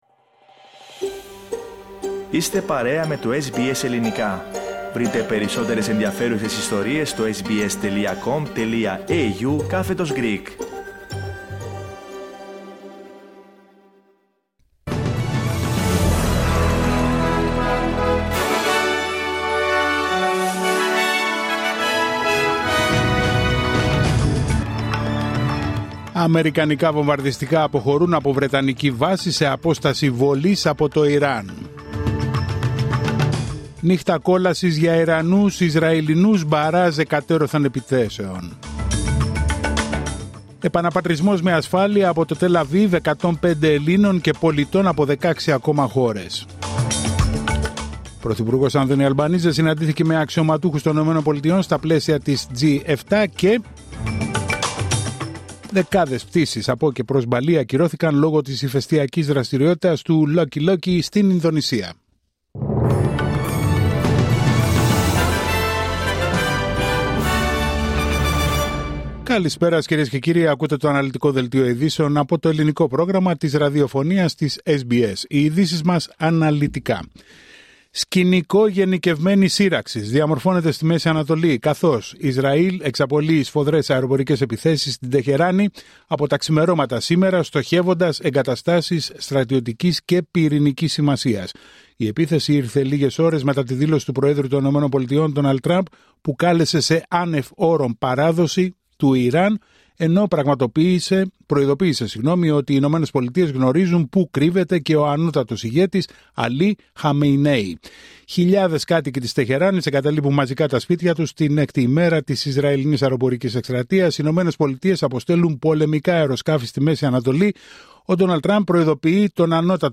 Δελτίο ειδήσεων Τετάρτη 18 Ιουνίου 2025